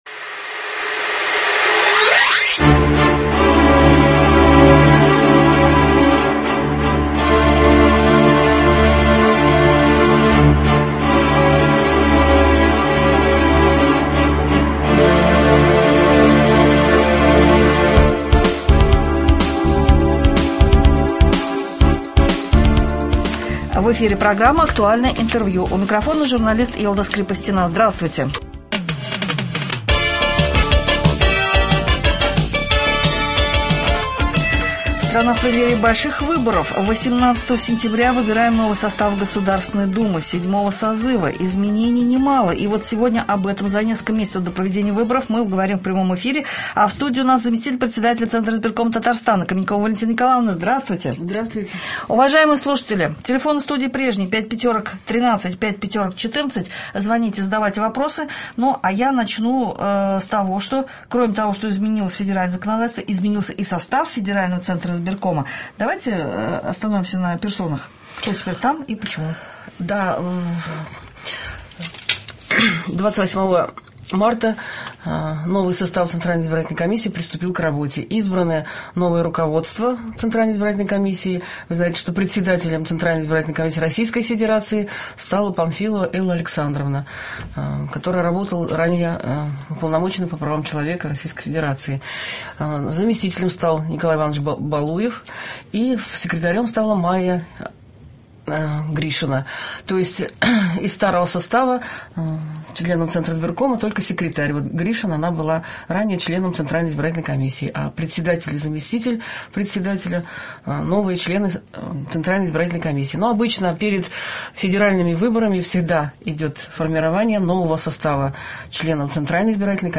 6 апреля 2016 года Заместитель Председателя Центральной избирательной комиссии Республики Татарстан В.Н.Каменькова приняла участие в прямом эфире программы «Актуальное интервью» на радио ГТРК «Татарстан». Представитель ЦИК республики рассказала о подготовке к выборам депутатов Государственной Думы Федерального Собрания Российской Федерации седьмого созыва.